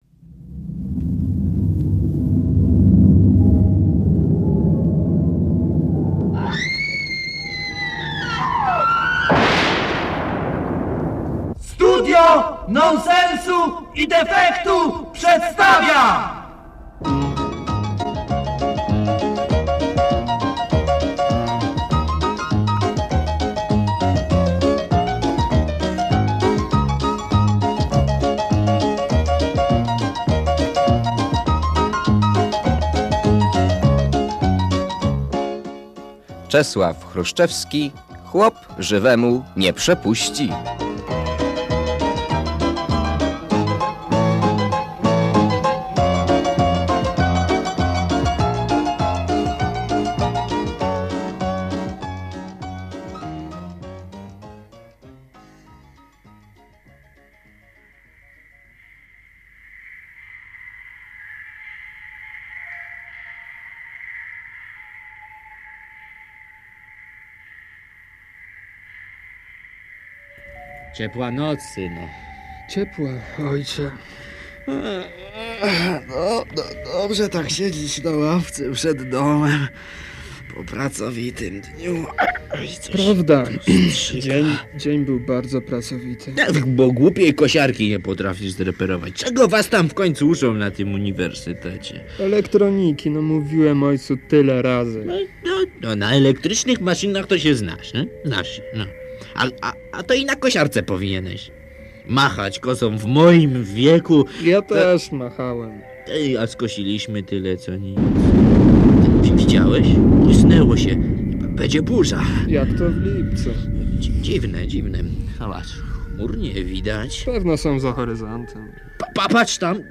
Słuchowisko z cyklu